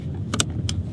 描述：锁在汽车的安全带上